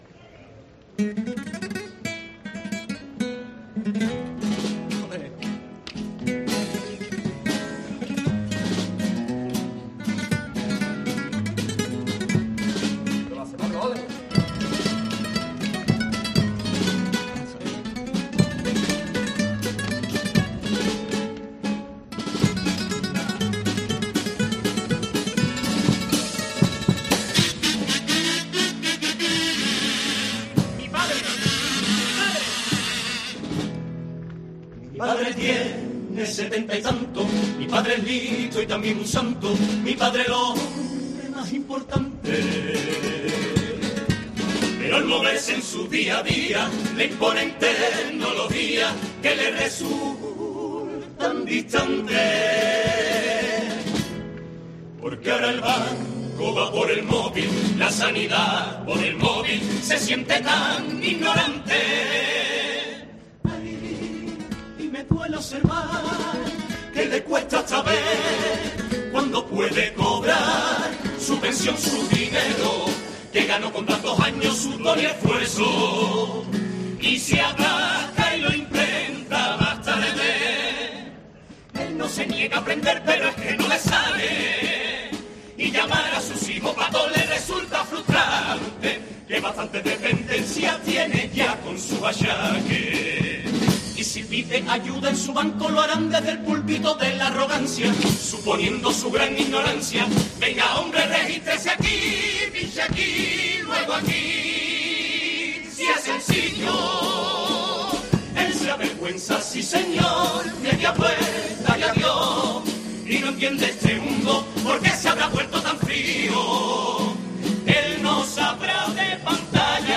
Pasodoble a la brecha digital de El club de los ignorantes